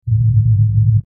Alien Spaceship Hum 02
Alien_spaceship_hum_02.mp3